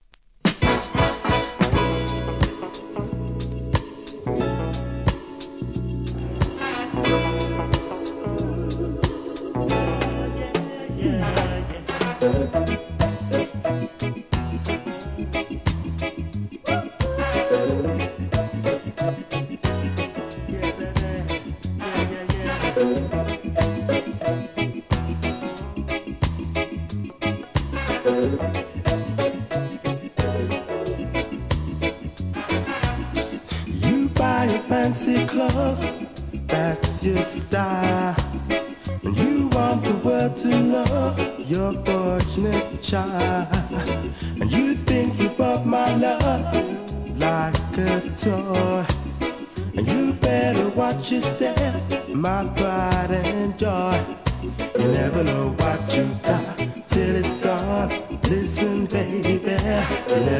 REGGAE
UK LOVERS CLASSIC!!